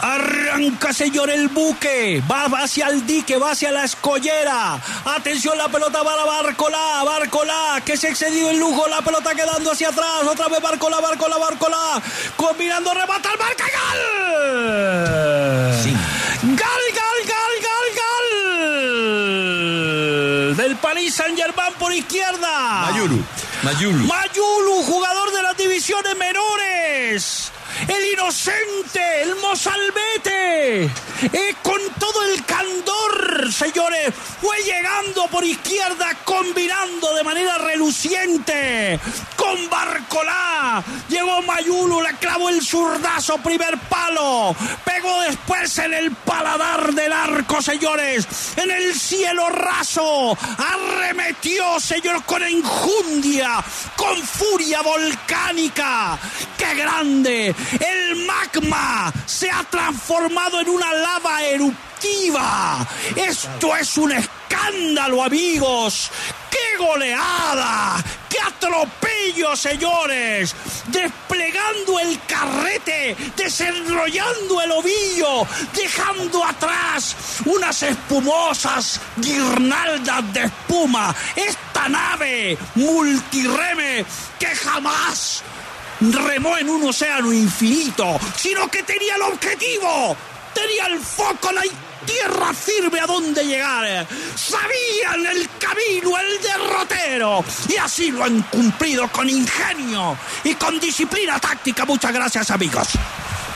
“Qué goleada, qué atropello”: Martín De Francisco ‘enloqueció’ con el 5-0 del PSG
Martín De Francisco en su narración la definición de Saenny Mayulu que ‘fulminó’ la final de la Champions League.